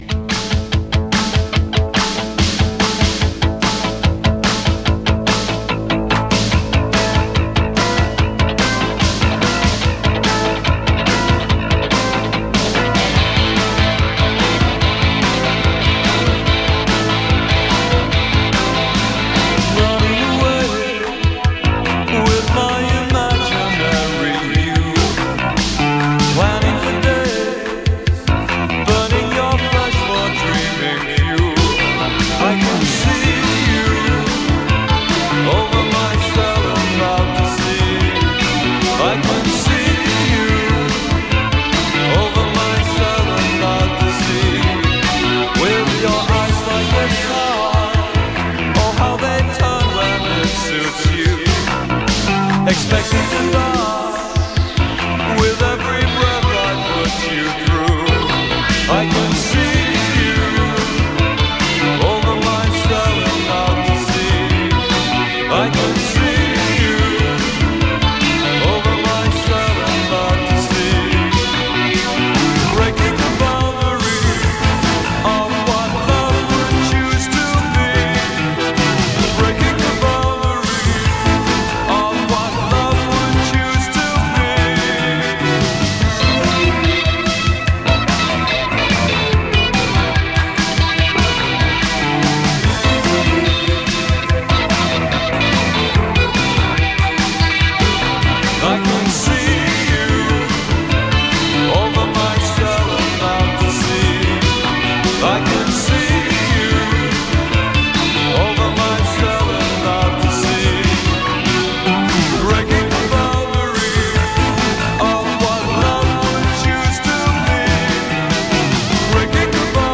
In session BBC Radio 1
English synthpop band
guitars, bass, keyboards
sax, keyboards